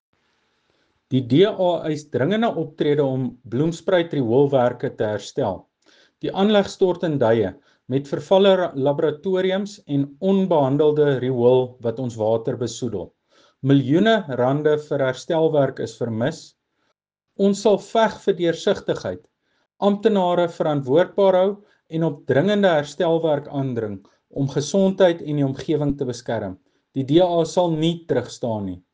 Afrikaans soundbites by Cllr Tjaart van der Walt and